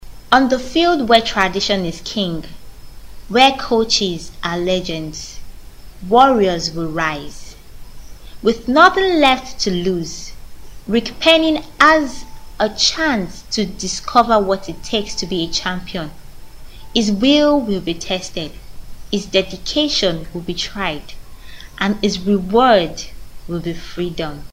女尼日利亚01 尼日利亚语女声 低沉|激情激昂|大气浑厚磁性|沉稳|娓娓道来|科技感|积极向上|时尚活力|神秘性感|调性走心|亲切甜美|感人煽情|素人|脱口秀